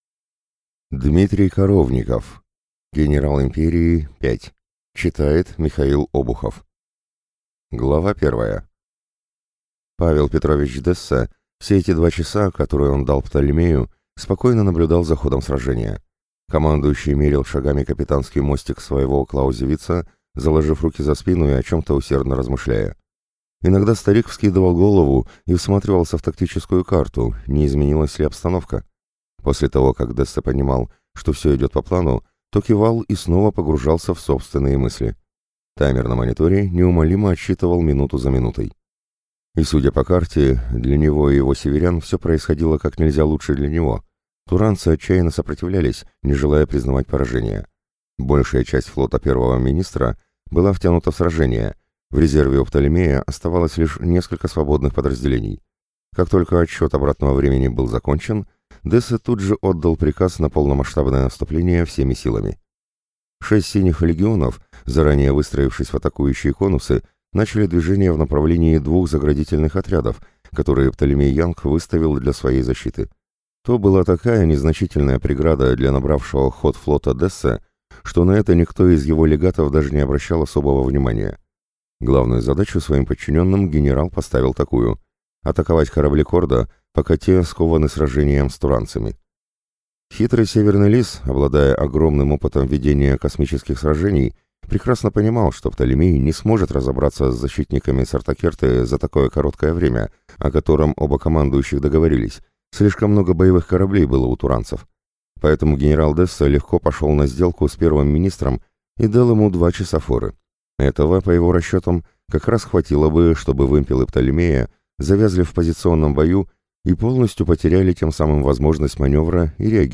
Аудиокнига Генерал Империи – 5 | Библиотека аудиокниг